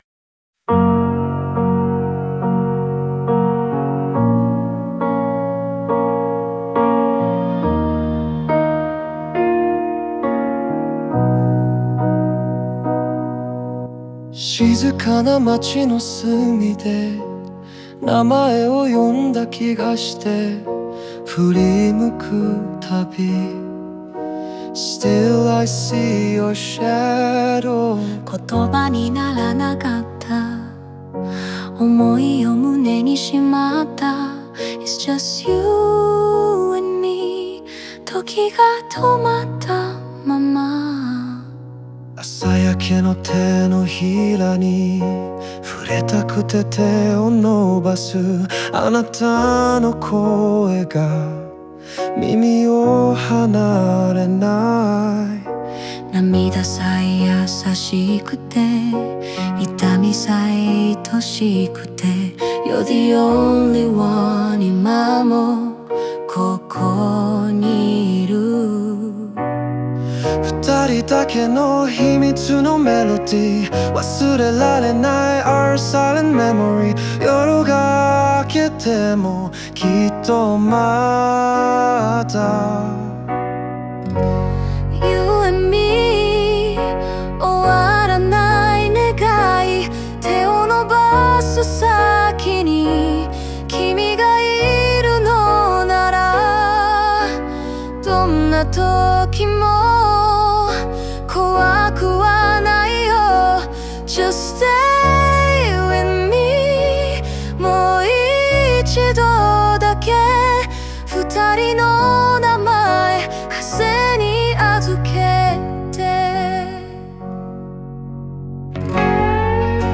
この楽曲は、過去に愛し合った二人が再び心を通わせる希望を描いたバラードです。
男女デュエットによって、互いの心の奥にある思いが響き合い、切なさと温もりを同時に伝える構成になっています。